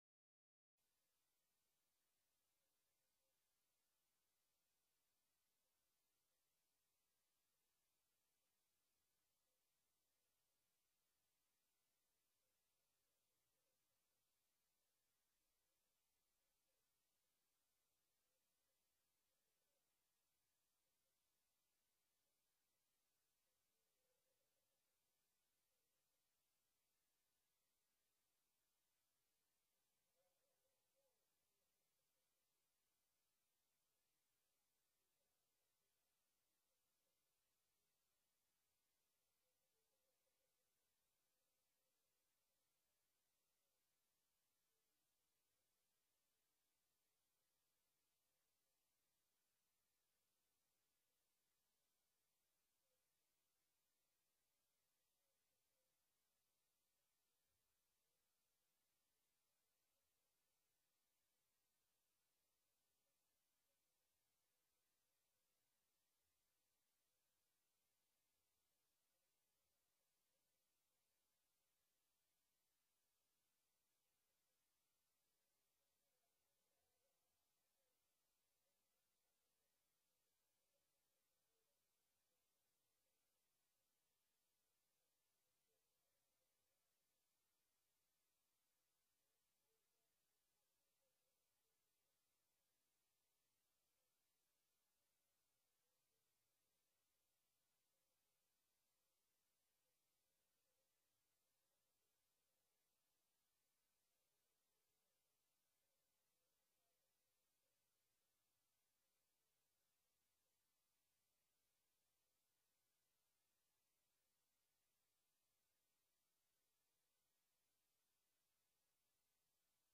Locatie: Raadzaal
Vergadering_7_november_2019___vervolg.mp3